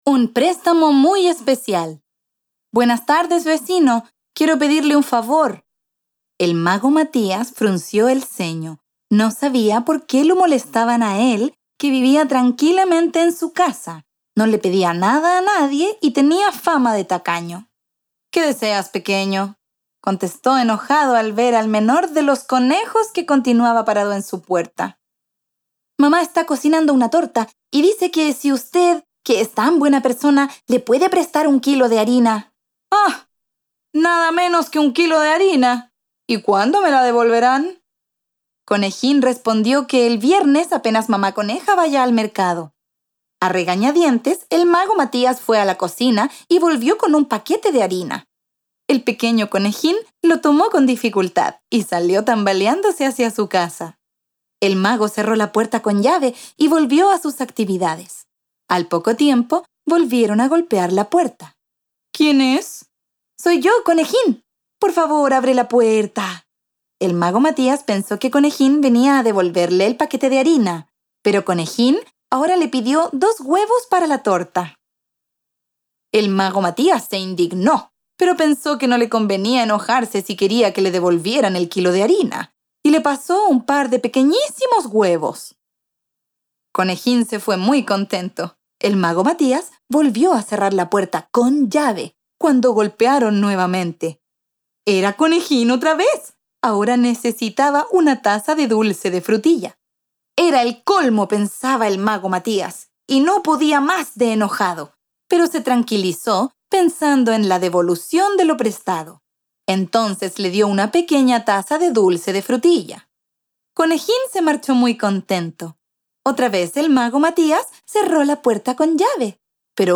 Audiolibro - Extracto Tomo 2
Audiolibro del texto "Un préstamo muy especial"